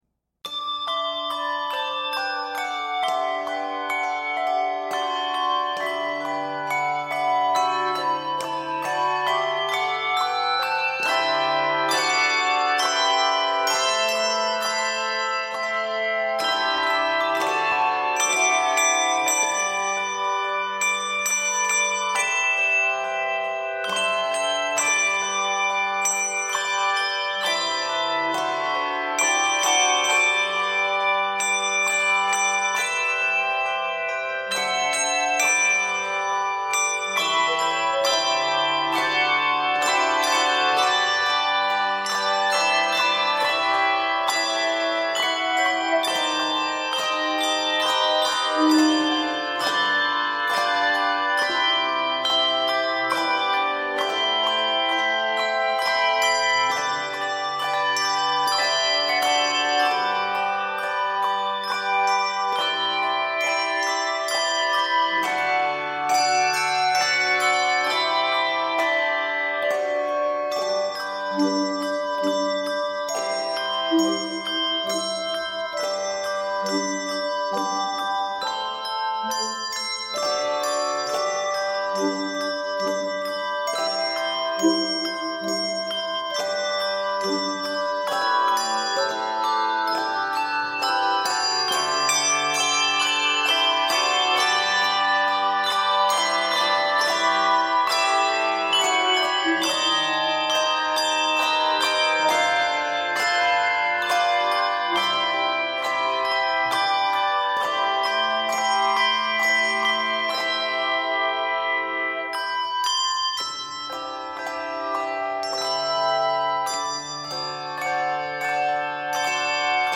Octaves: 3-5